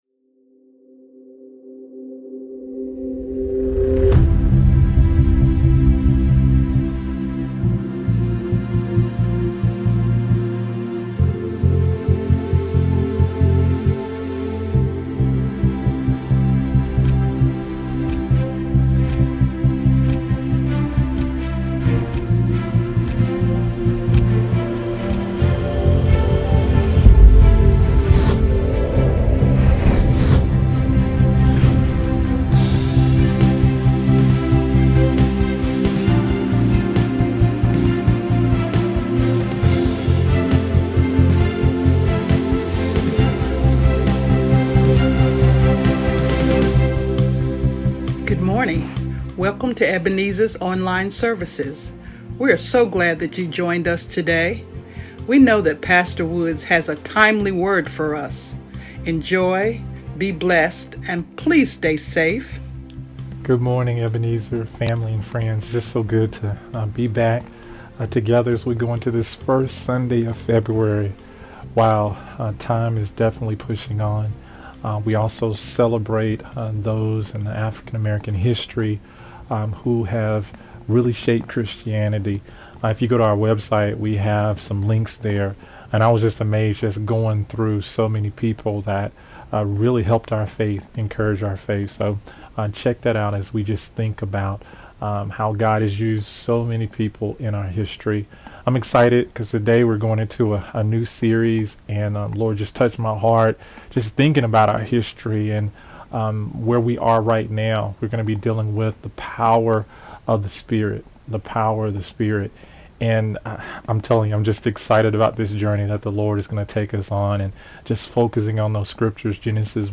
Sermons | Ebenezer Baptist Church